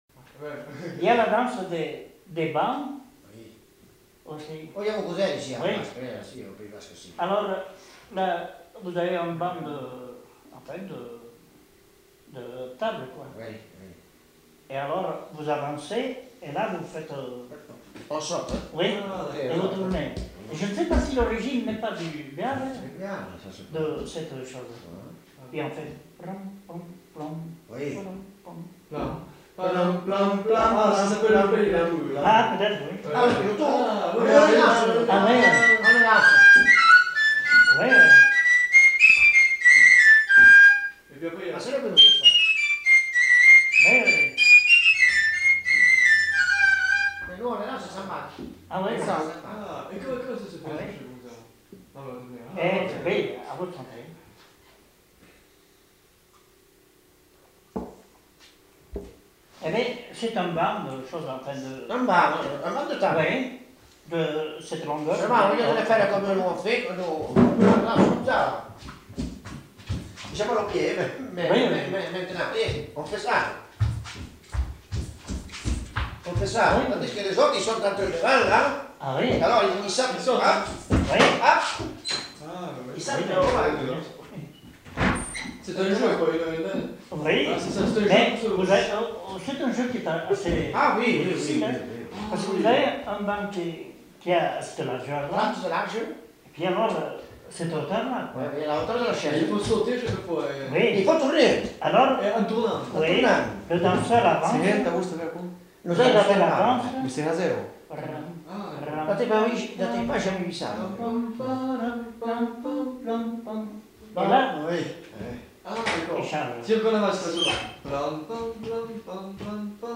Aire culturelle : Pays Basque
Lieu : Trois-Villes
Genre : témoignage thématique
Notes consultables : En milieu de séquence, peiroton est fredonné par deux hommes et joué à la txirula.